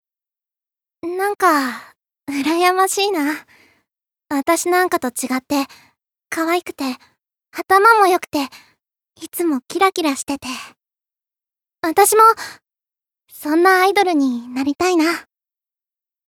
Voice Sample
セリフ２